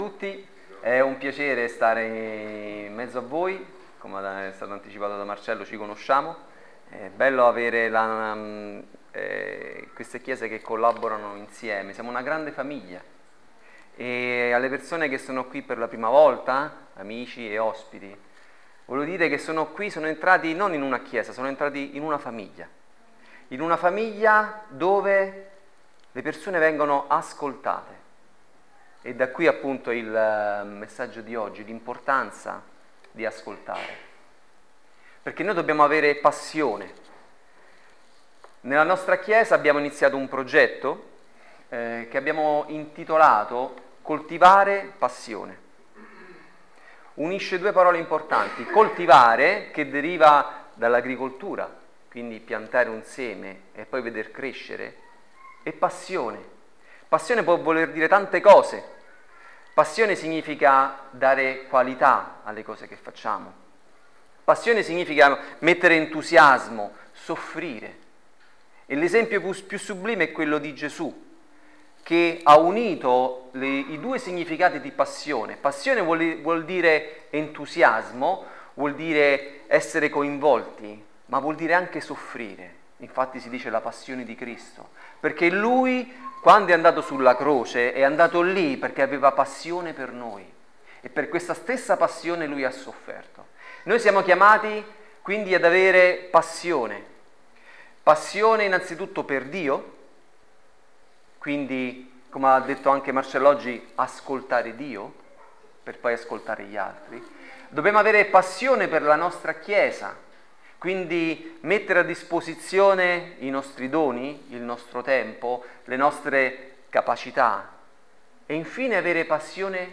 L’importanza di ascoltare – Messaggio